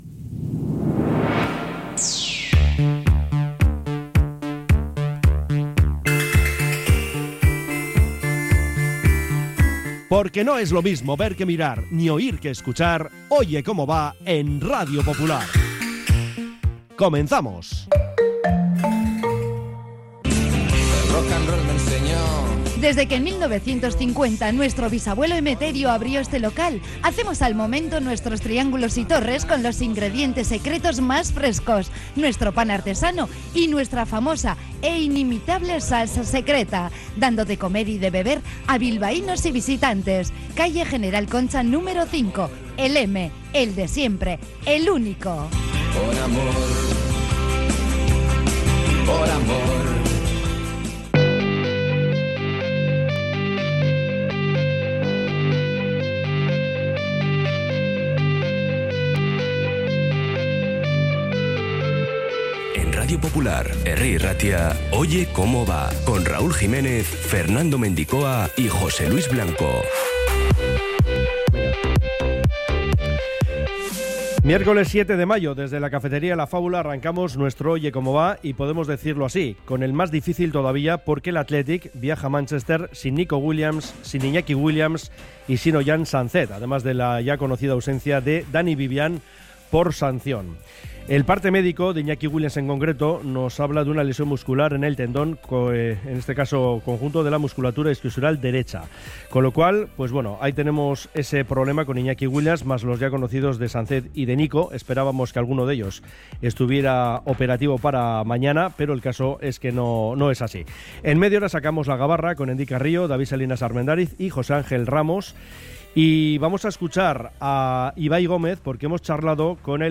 Oye Cómo Va 07-05-25 | Última hora del United-Athletic y entrevista a Ibai Gómez